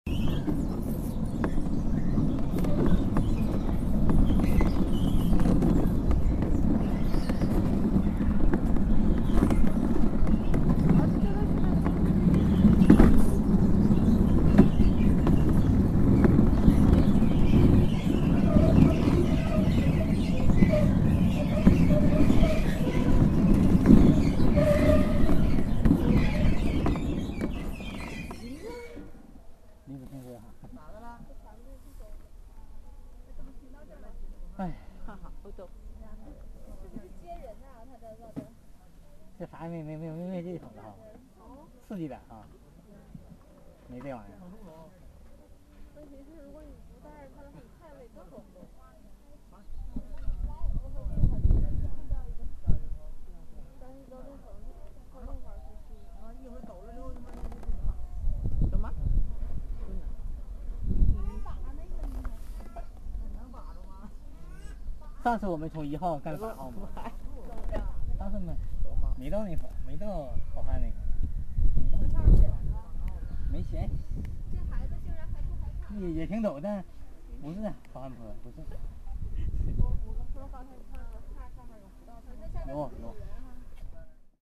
These recordings were taken mostly in Beijing, in the spring 2007 – the Great Wall implied a four-hour bus drive.
Field Recording Series by Gruenrekorder
great_wall.mp3